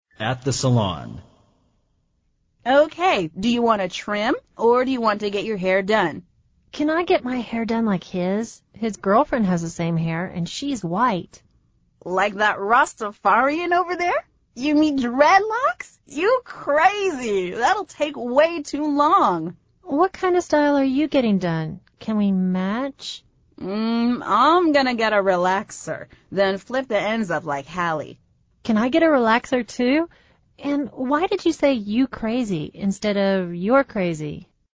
美语会话实录第234期(MP3+文本):Get your hair done